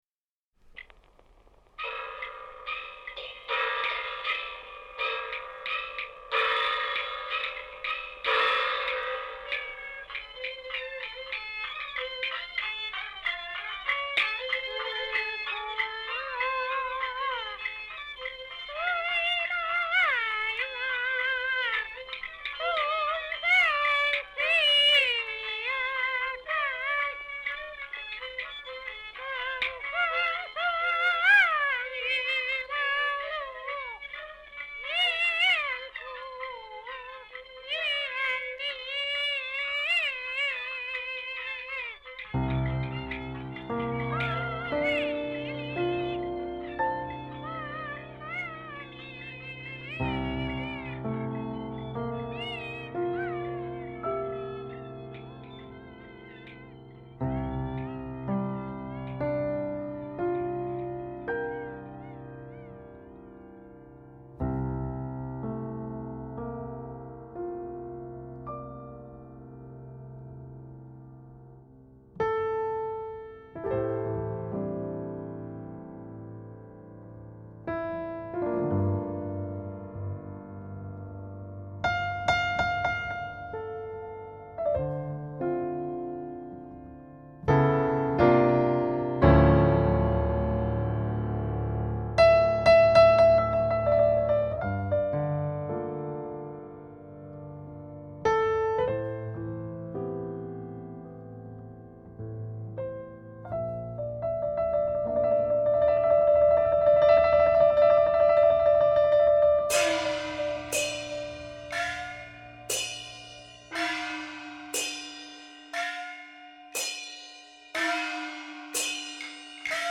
★ 匯集多種音樂元素，中國優秀爵士音樂家鼎力相助，再現正宗古巴爵士樂風音樂！
烘托爵士現場的微妙氛圍。